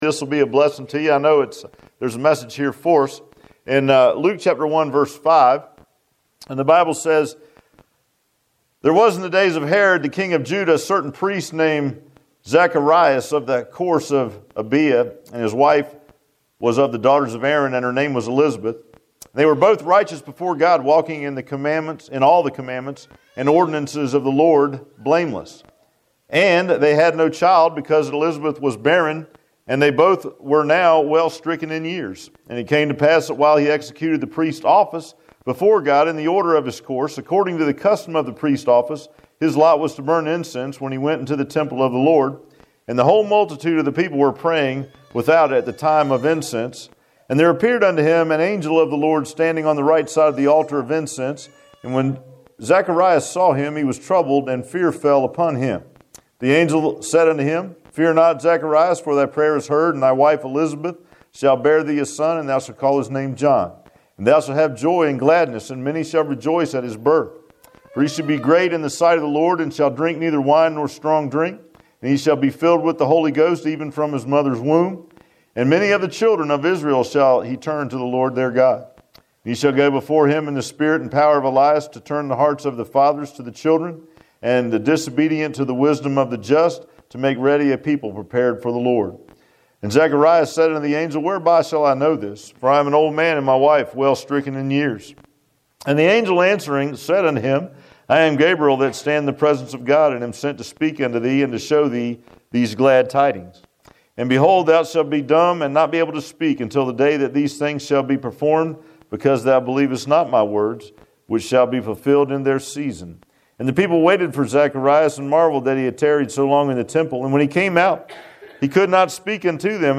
Luke 1:5-27 Service Type: Sunday AM Bible Text